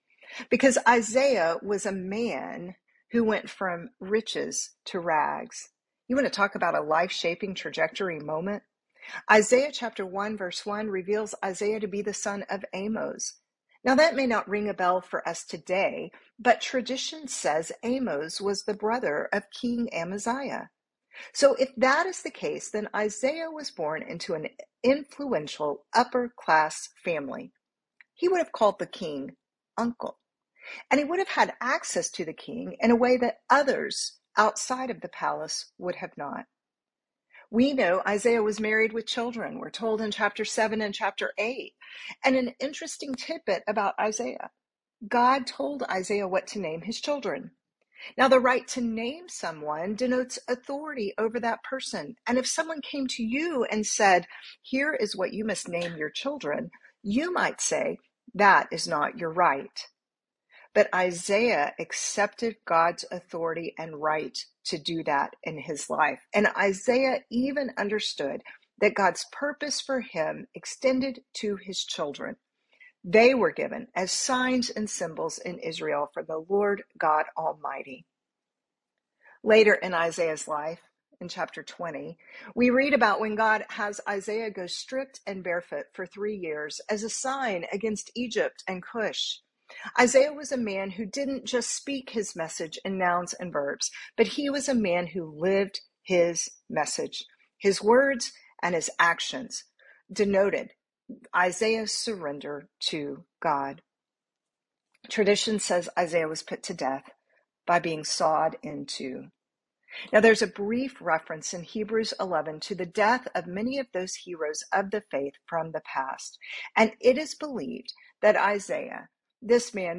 Portions of this lecture may be used in part by BSF teaching teams or online groups.